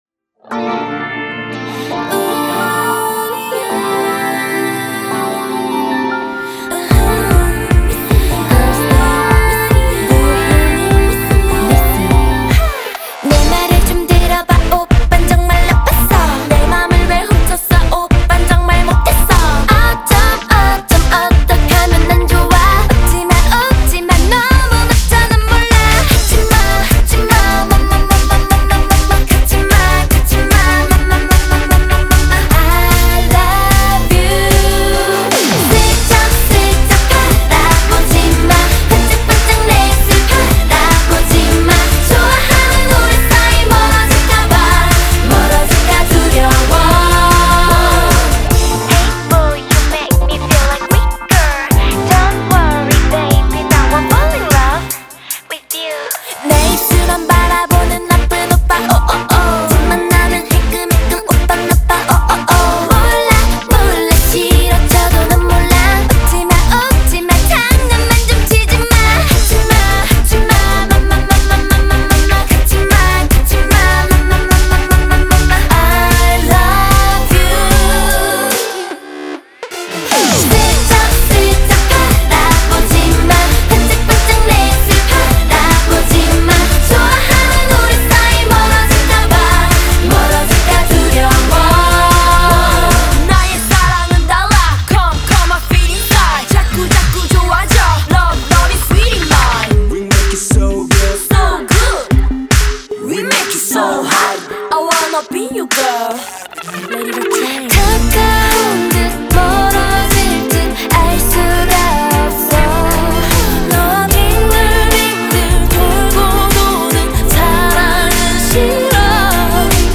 퇴근 직전 받은 귀엽고 상큼한 노래 한 곡…